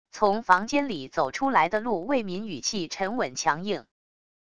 从房间里走出来的陆为民语气沉稳强硬wav音频